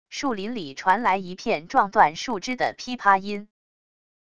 树林里传来一片撞断树枝的噼啪音wav音频